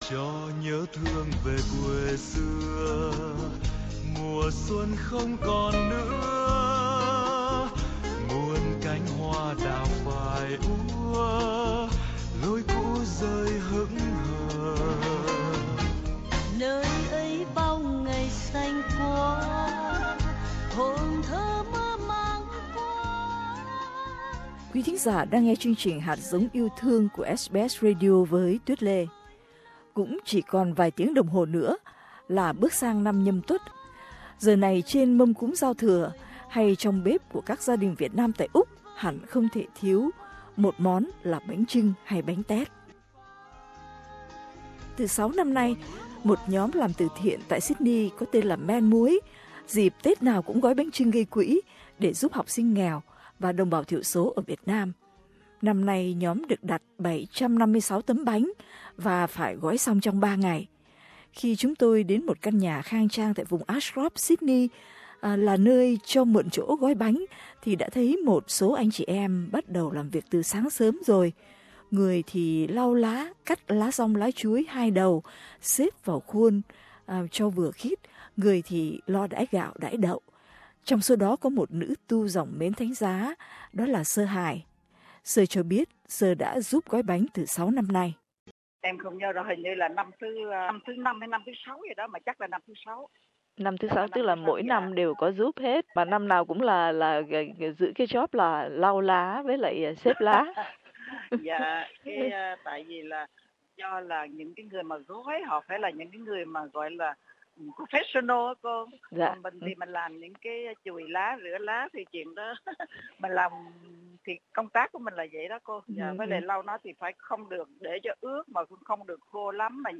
Khi chúng tôi đến một căn nhà khang trang vùng Ashcroft được dùng làm nơi gói và nấu bánh thì đã thấy chừng hơn chục người đã đến làm từ sáng sớm.